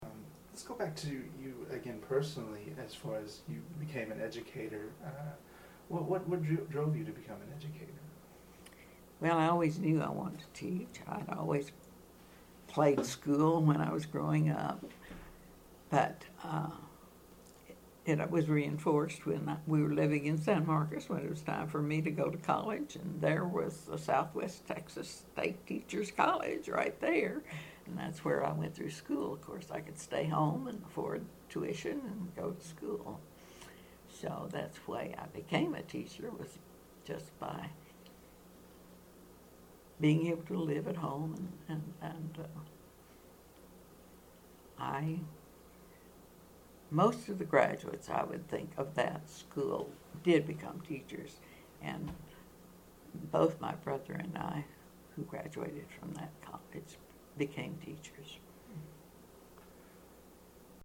Excerpt of an Oral History Interview